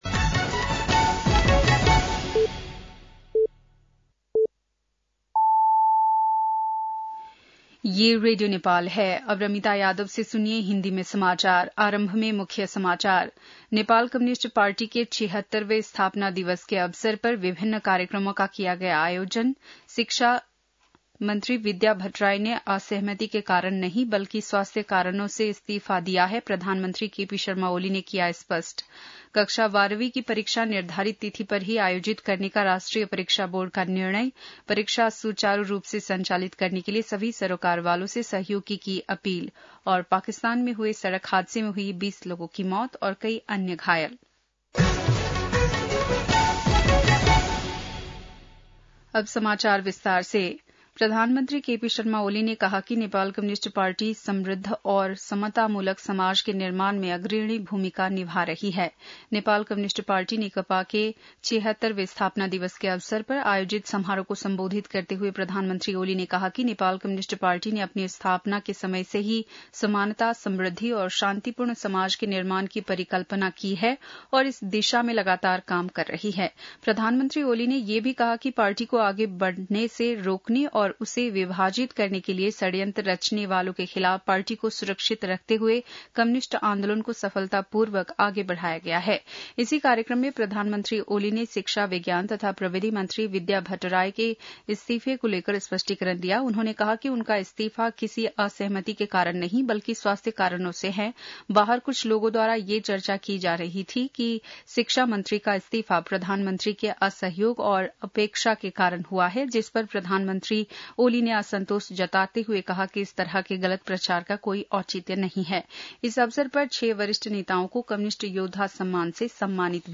बेलुकी १० बजेको हिन्दी समाचार : ९ वैशाख , २०८२
10-pm-hindi-news-1-09.mp3